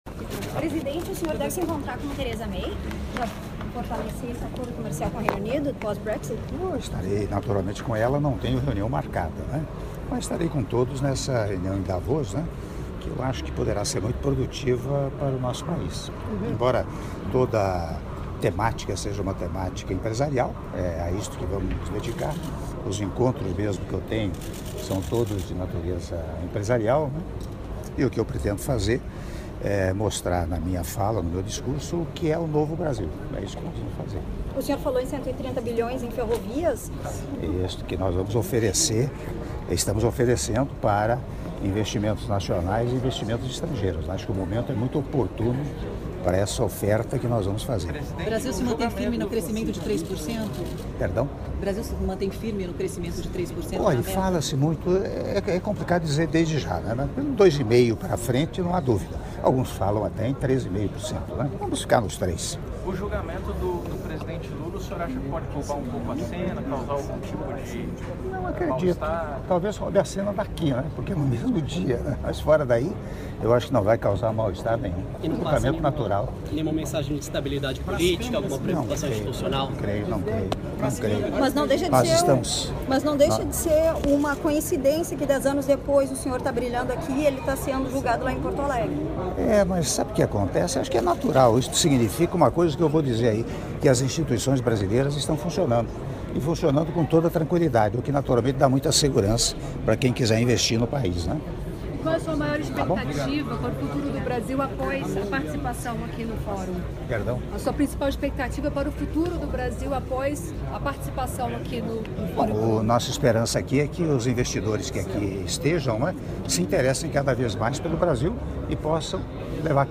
Áudio da entrevista coletiva concedida pelo Presidente da República, Michel Temer, na chegada do Hotel Park Hyatt Zürich - Zurique/Suíça - (02min03s)